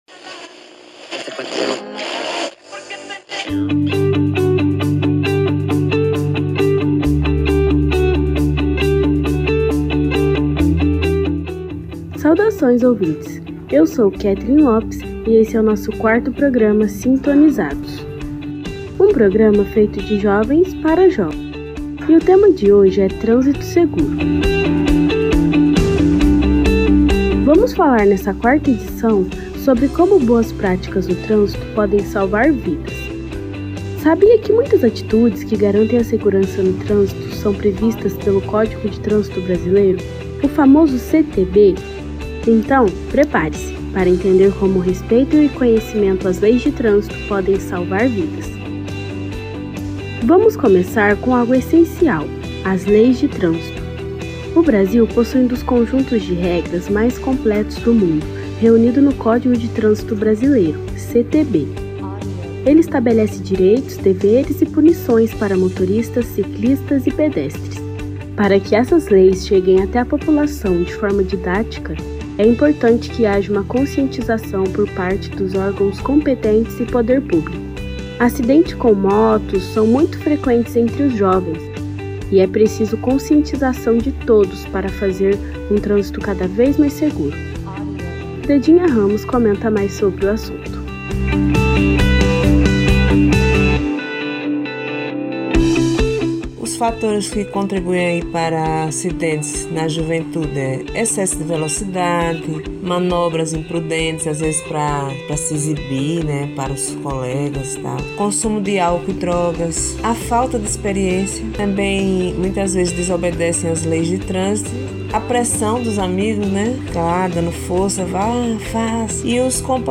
Neste quarto episódio do Sintonizados, programa feito de jovens para jovens, saiba como boas práticas no trânsito podem salvar vidas.